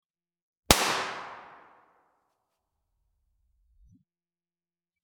Gunshot 03
Gunshot_03.mp3